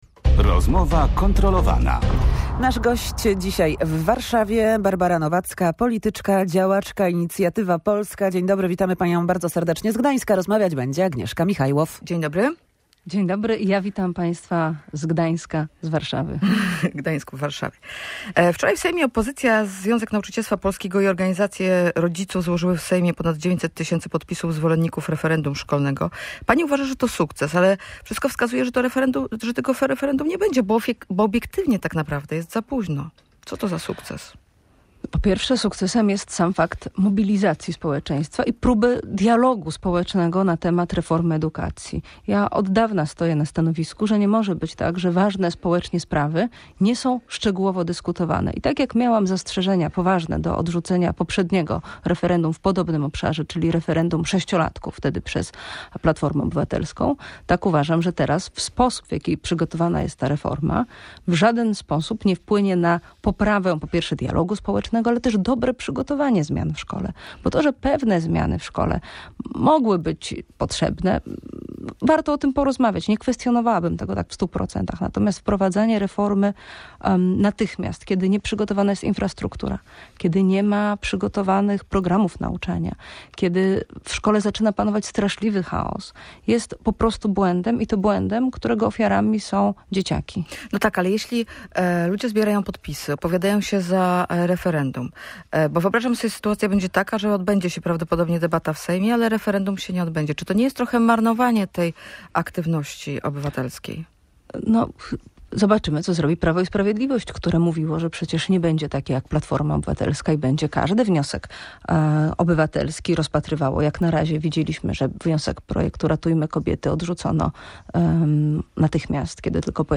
– Sukcesem jest sam fakt mobilizacji społeczeństwa i próby dialogu społecznego na temat reformy edukacji – tak o wniosku o przeprowadzenie referendum mówiła w Radiu Gdańsk Barbara Nowacka z Inicjatywy Polskiej.